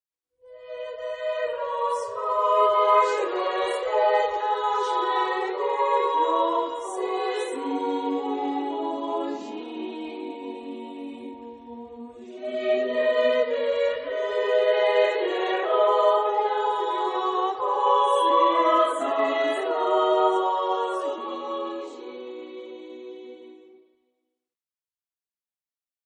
Genre-Style-Form: Christmas song ; Partsong ; Lullaby
Mood of the piece: andante cantabile ; calm
Type of Choir: SSAA  (4 children OR women voices )
Tonality: B major